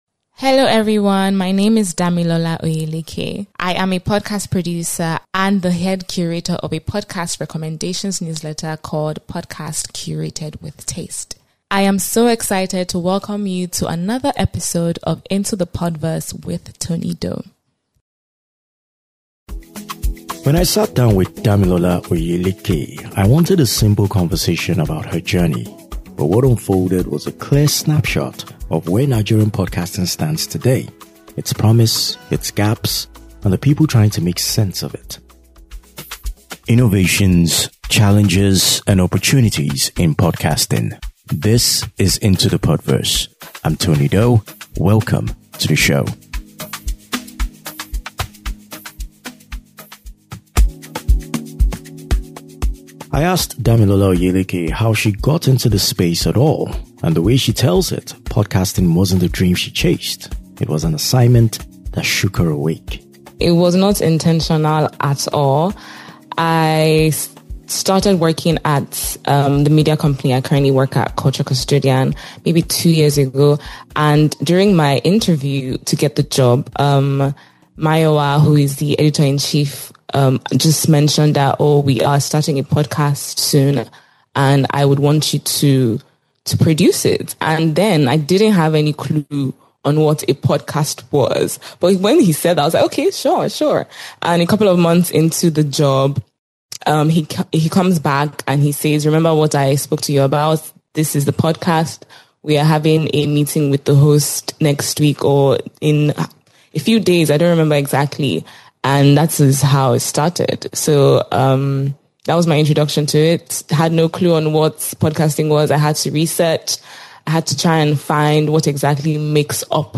They unpack Podfest Naija, the push for better curation, and the value of documenting creators' journeys. It's a simple, honest conversation about building a real podcast industry one brick at a time.